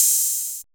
808BRT OHH.wav